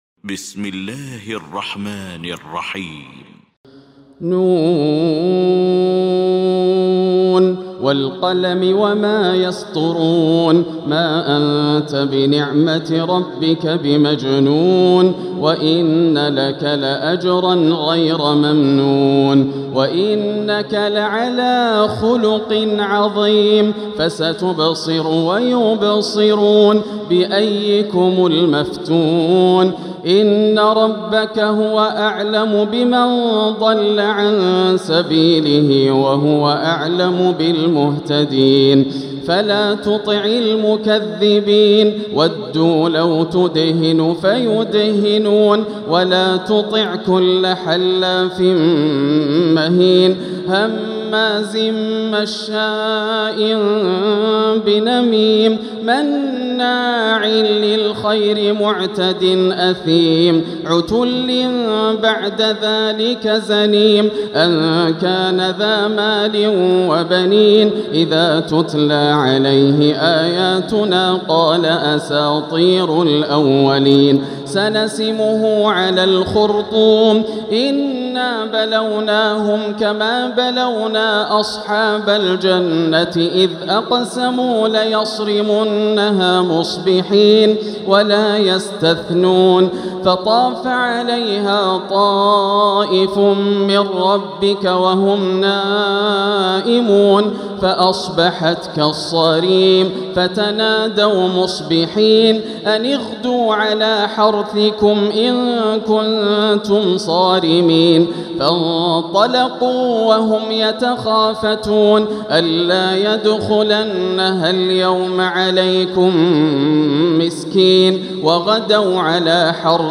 المكان: المسجد الحرام الشيخ: فضيلة الشيخ ياسر الدوسري فضيلة الشيخ ياسر الدوسري القلم